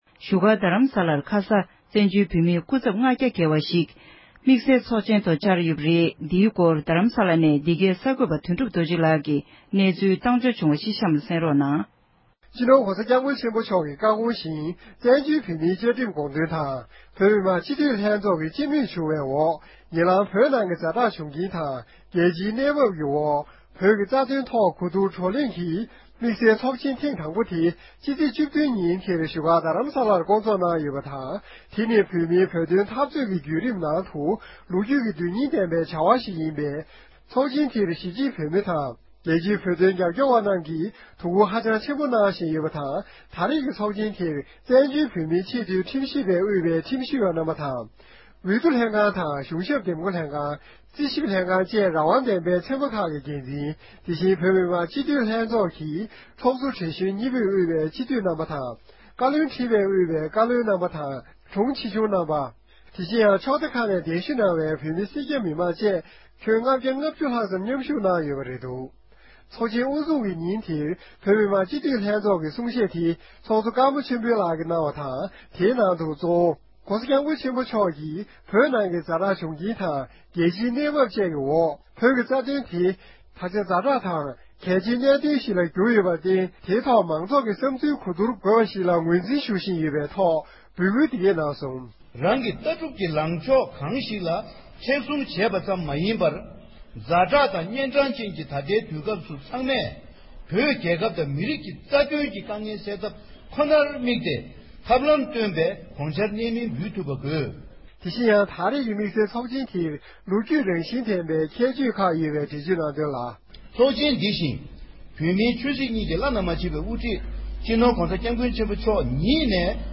གསར་འགྱུར་ལ་གསན་རོགས་གནང་།